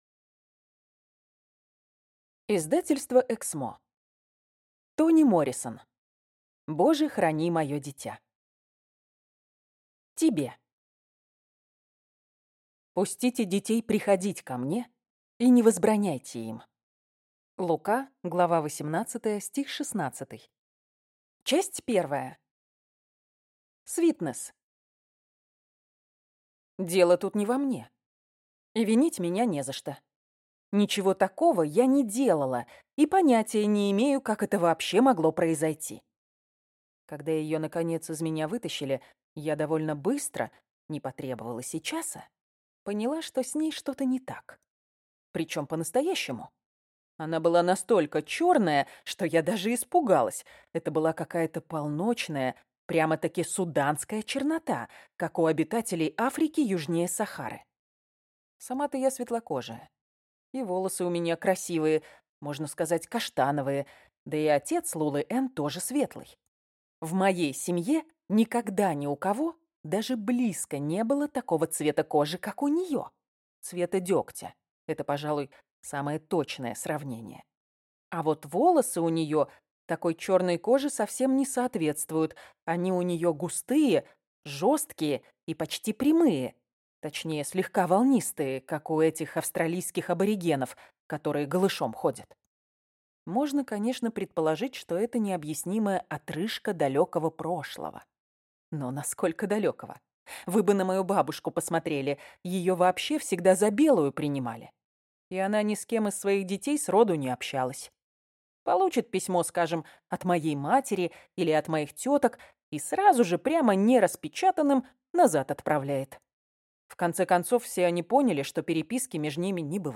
Аудиокнига Боже, храни мое дитя | Библиотека аудиокниг